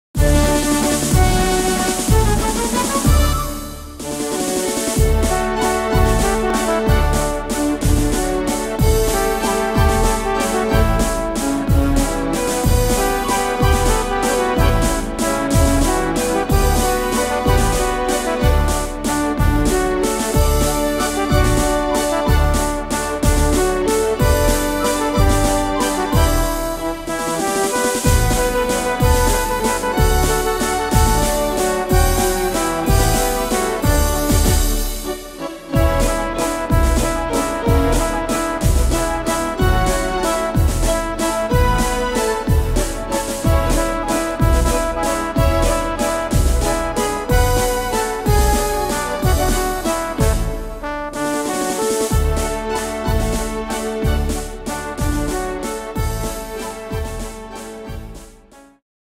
Tempo: 187 / Tonart: D-Dur